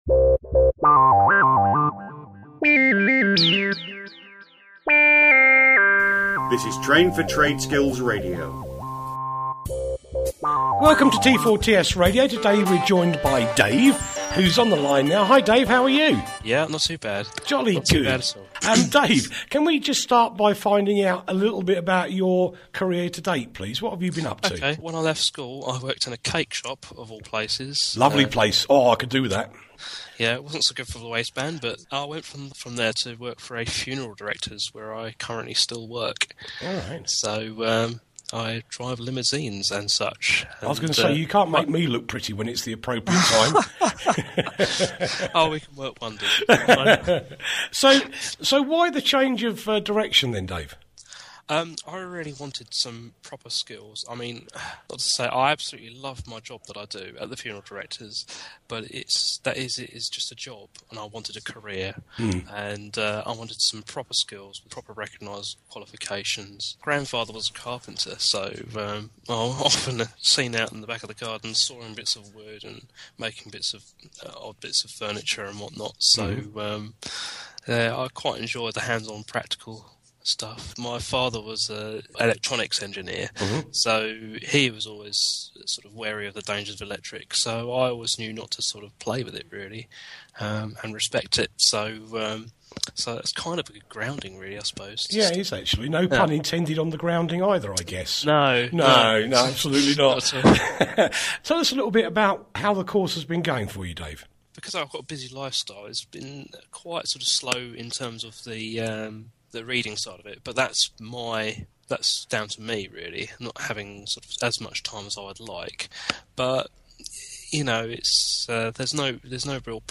Train4TradeSkills Student Radio brings students talking about their experiences whilst studying and attending practical training. Many offer tips on getting work experience or jobs - some explain how to start a business and other explaining how including solar training can benefit your career.